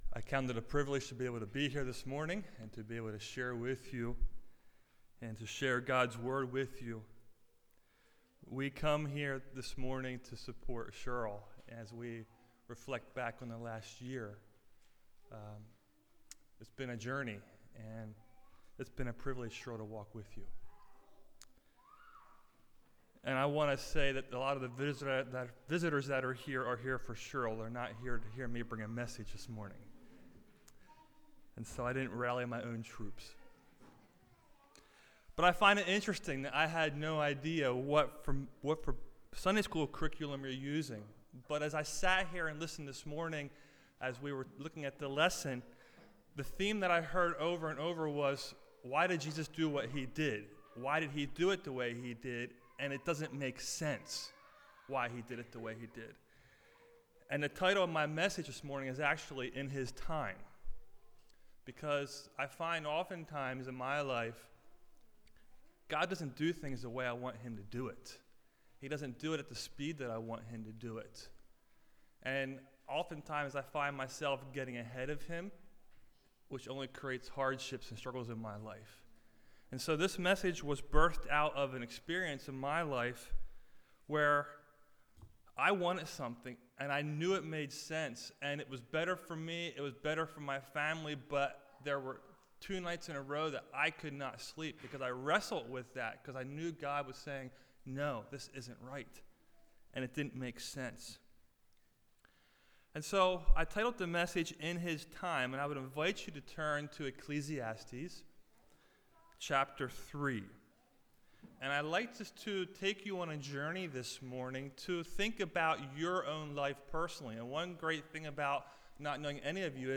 In His Time - New Hope Mennonite Church